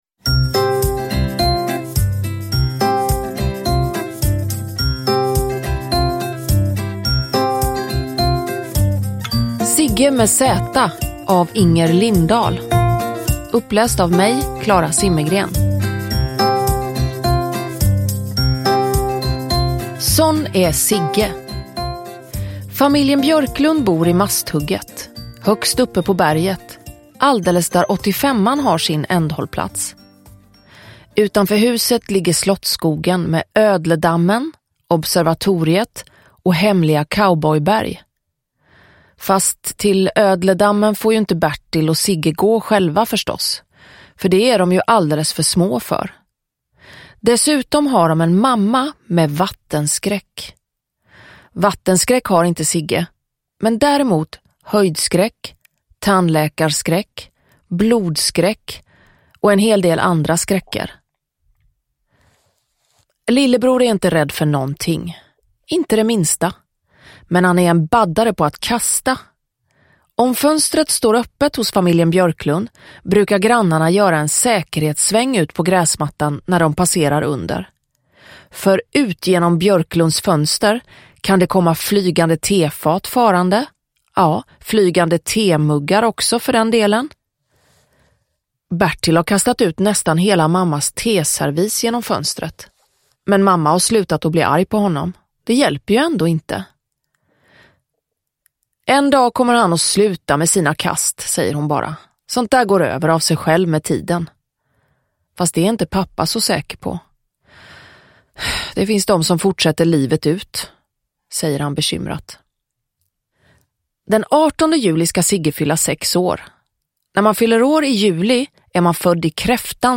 Zigge med zäta – Ljudbok – Laddas ner